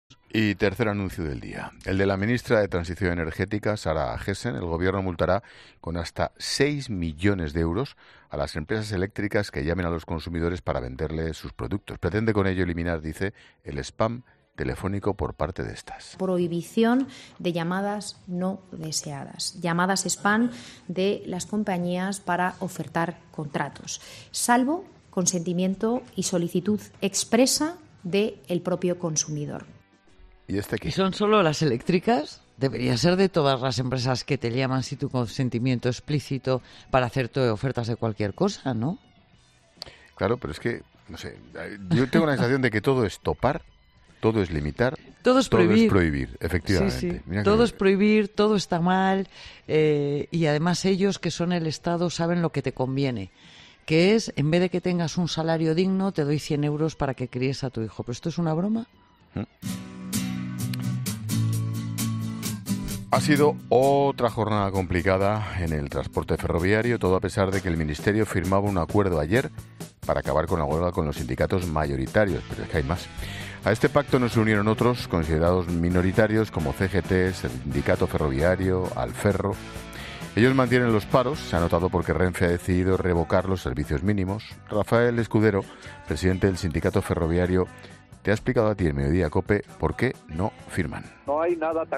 Expósito aprende en Clases de Economía de La Linterna con la experta económica y directora de Mediodía COPE, Pilar García de la Granja, sobre la prohibición de spam telefónico por parte de las eléctricas